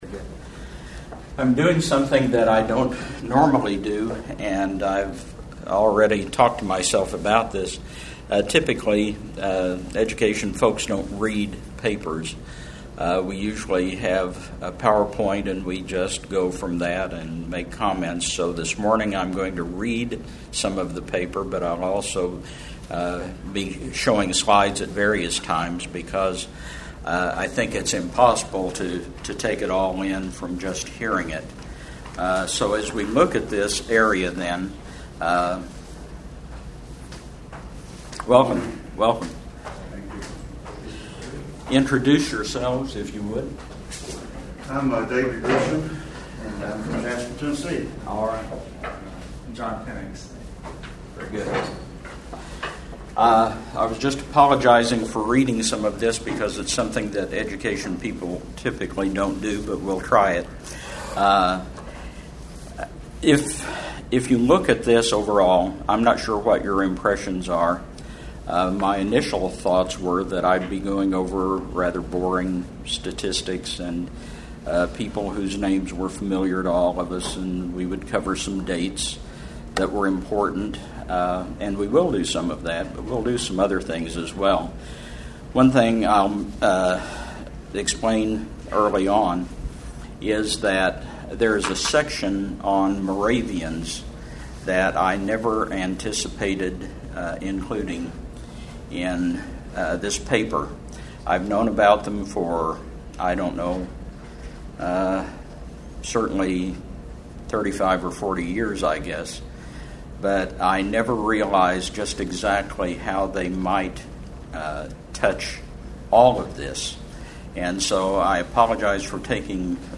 KJV400 Festival
Address: The King James Bible: Influences on Early American Education Recording Date